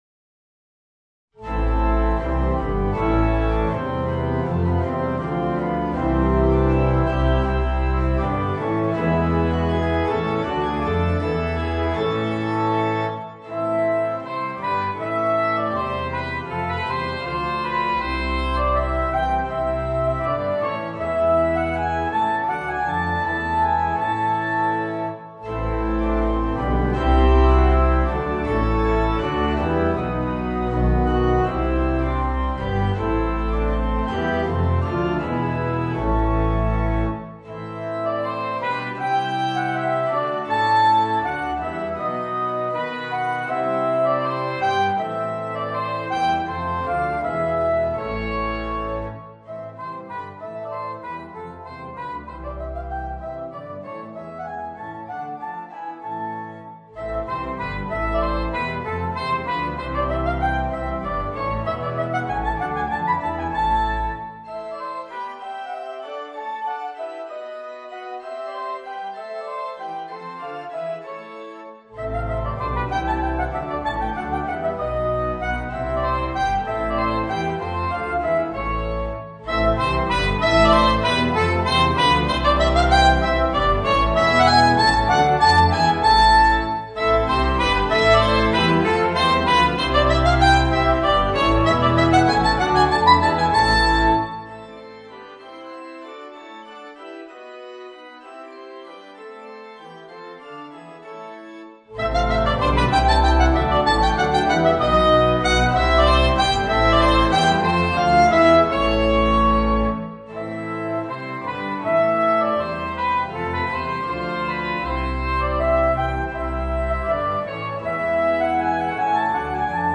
für Sopransaxophon und Klavier oder Orgel